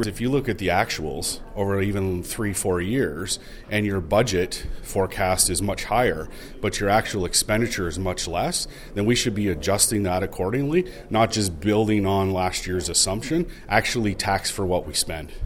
Councillor Carr tells Quinte News there needs to be a change in the process.